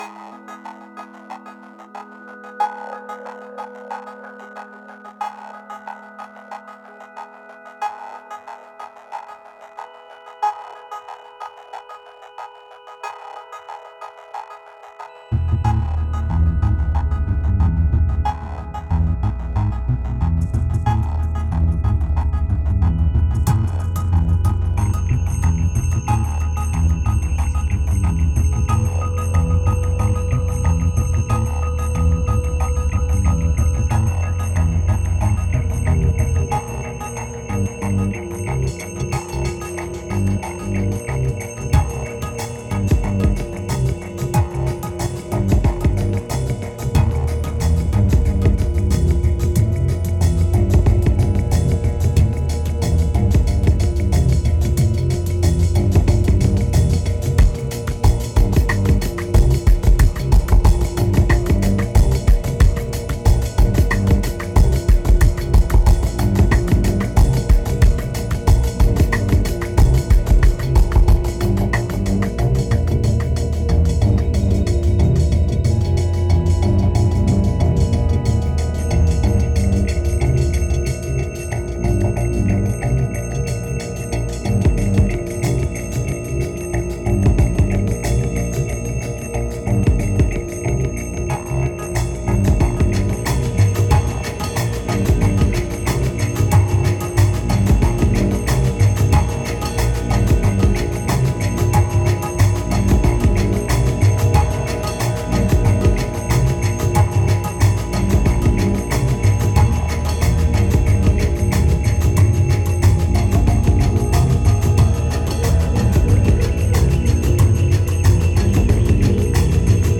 2280📈 - 70%🤔 - 92BPM🔊 - 2011-11-16📅 - 136🌟